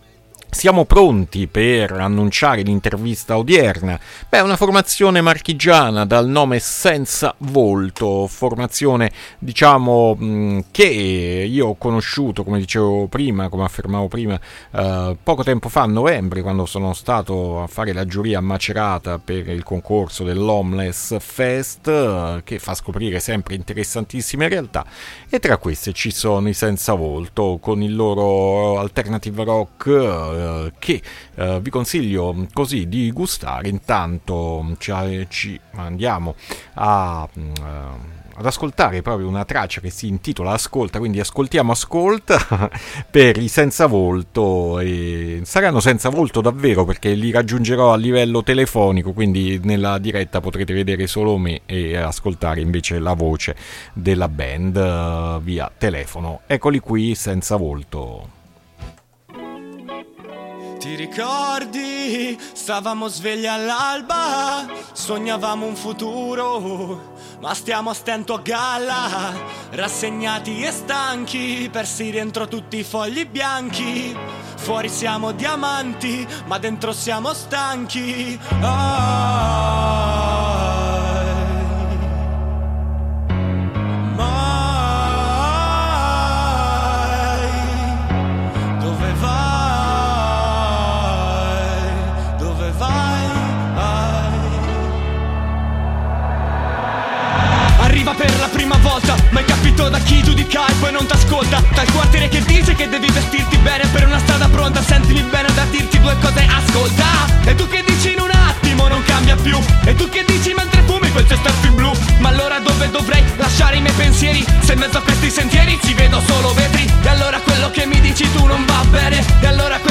Ne è nata così un’occasione per far conoscere la band all’interno di Alternitalia con una bella chiacchierata e l’ascolto di tre brani.